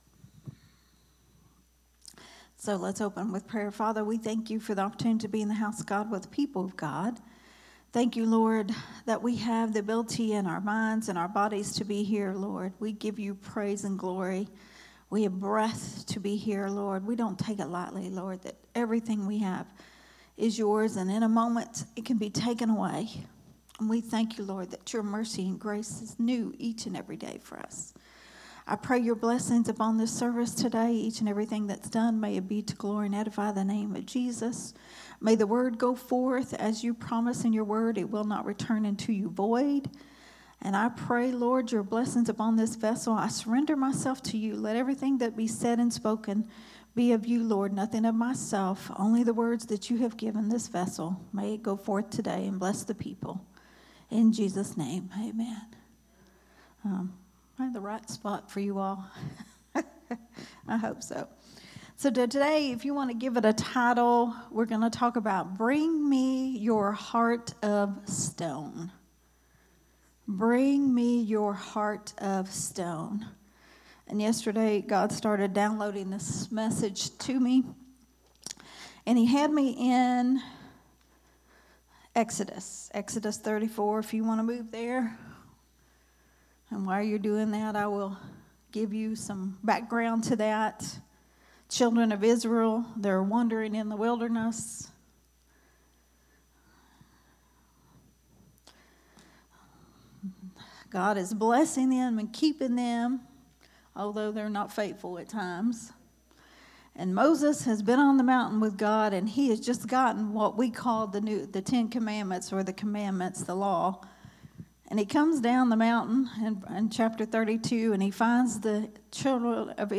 A Sunday Morning Risen Life teaching
recorded at Growth Temple Ministries on July 20th